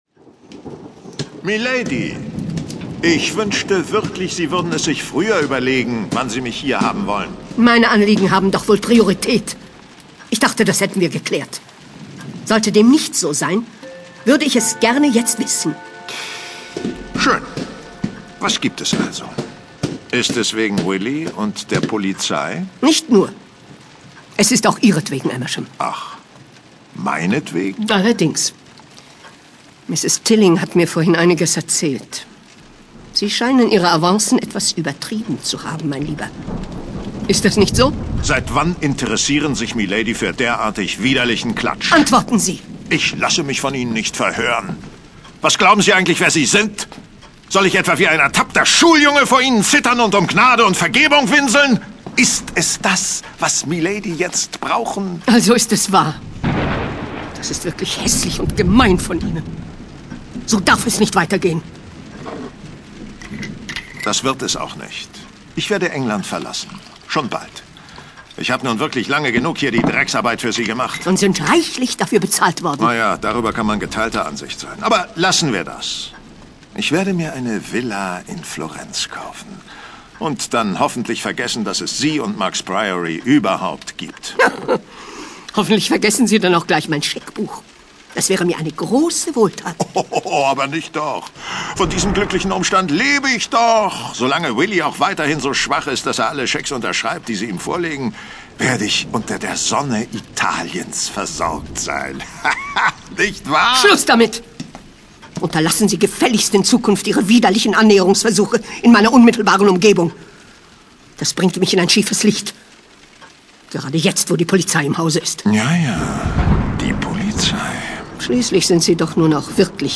Krimiklassiker. Hörspiel
Neben seiner Bühnentätigkeit ist er ein gefragter Synchron- und Hörspielsprecher mit einer unverwechselbaren, markanten Stimme.